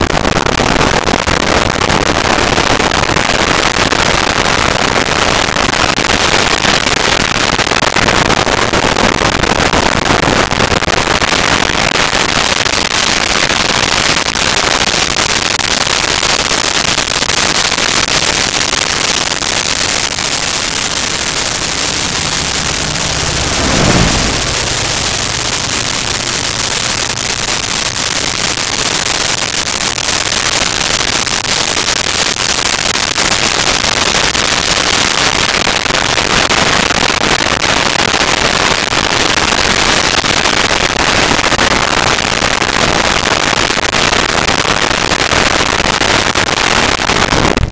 Prop Plane Low Fast By
Prop Plane Low Fast By.wav